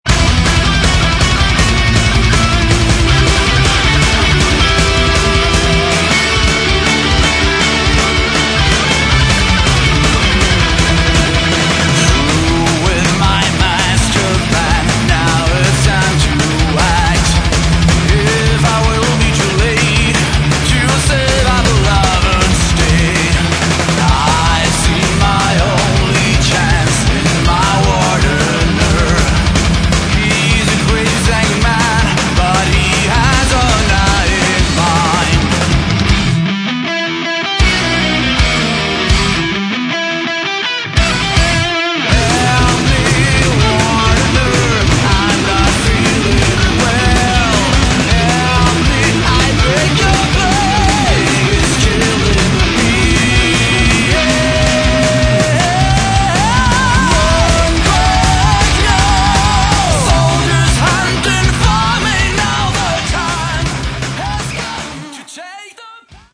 Metal
Древняя битва между Добром и Злом в стиле Speed Power Metal!